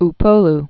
U·po·lu
(-pōl)